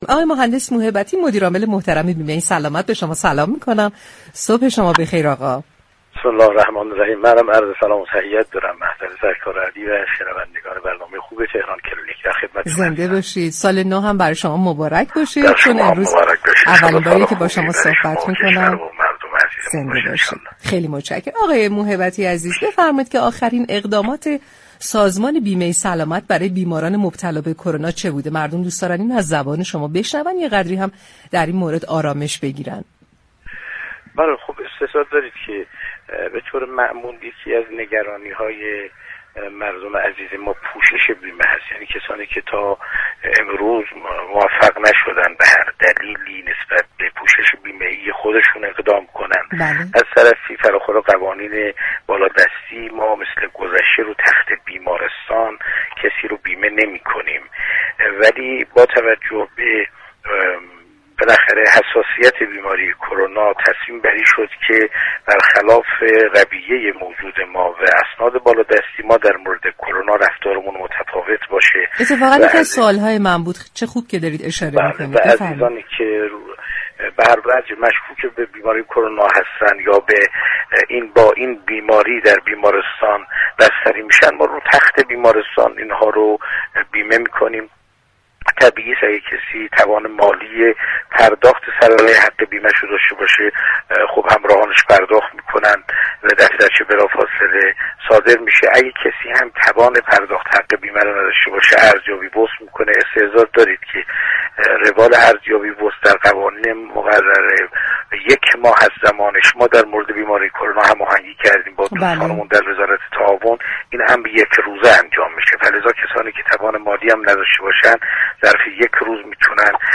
مدیرعامل بیمه سلامت در گفتگوی تلفنی با برنامه تهران كلینیك رادیو تهران آخرین تمهیدات این بیمه برای بیماران مبتلا به كرونا را تشریح كرد.